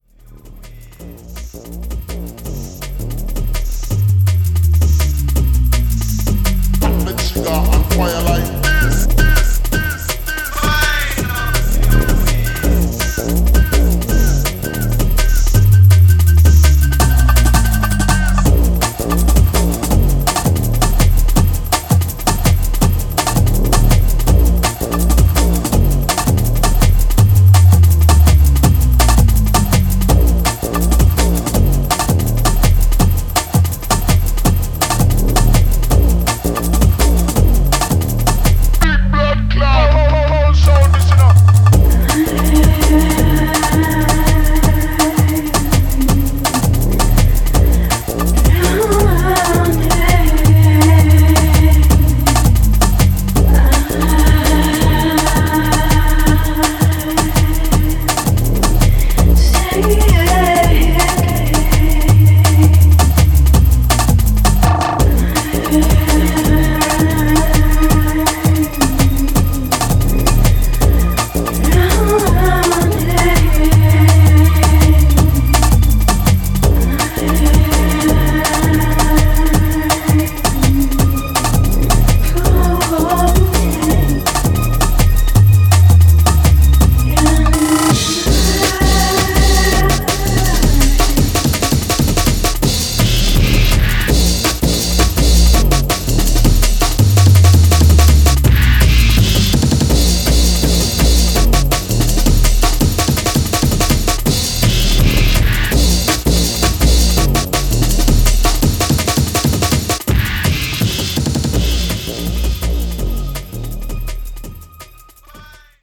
blending a bevy of shared influences in an original style.
Jungle